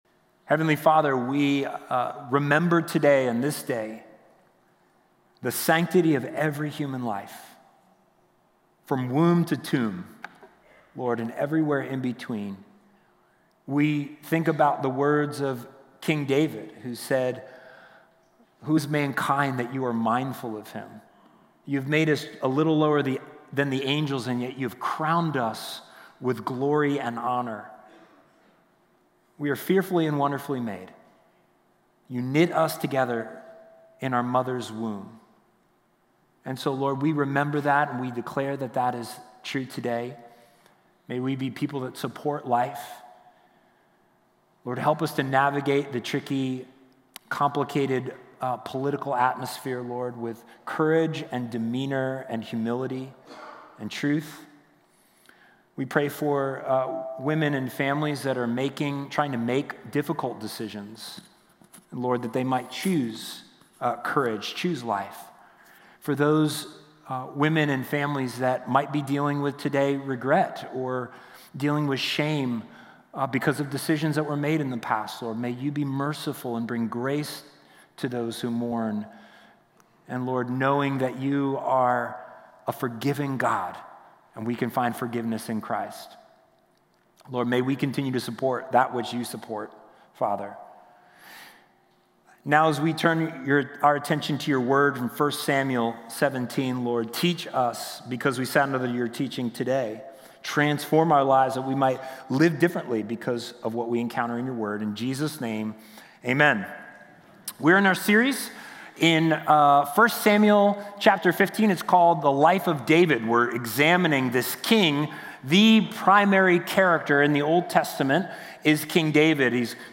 Listen to Sunday sermons from Brandywine Valley Church in Wilmington, Delaware.